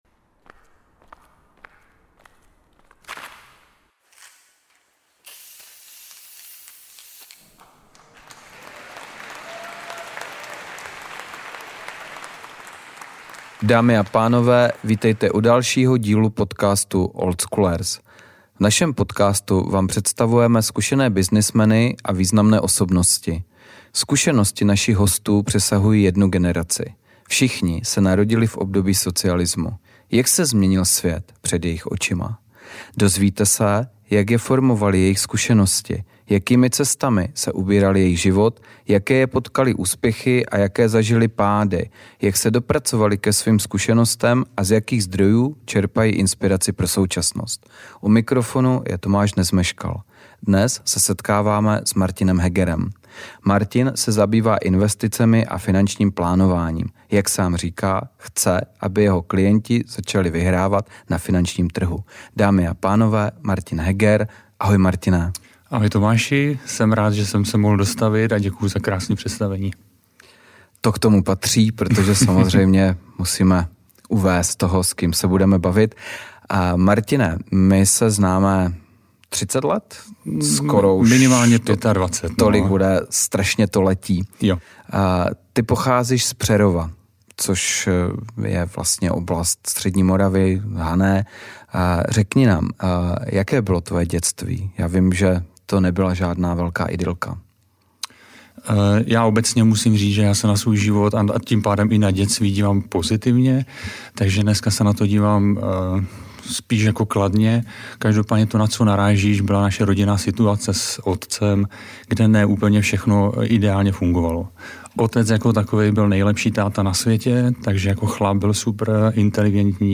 Tento rozhovor půodně vznikl v roce 2021.